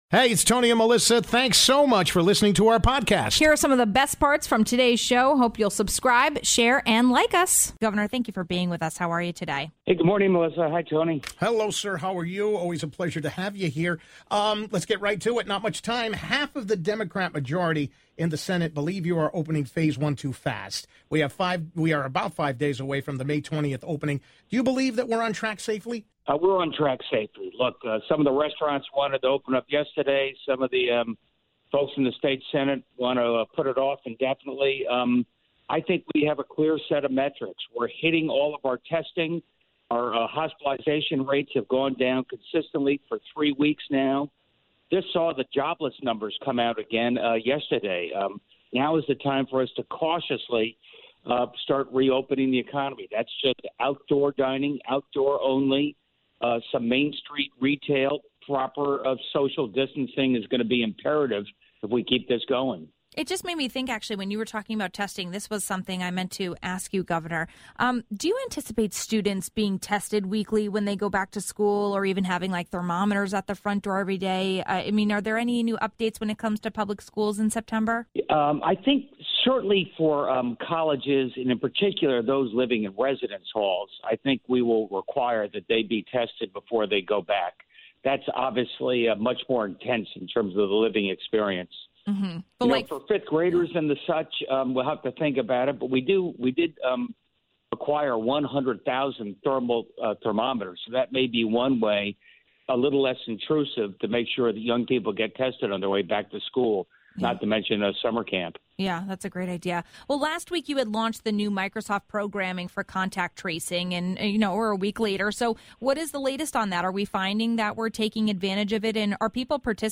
1. Governor Lamont answers the latest questions on covid-19. ((00:08))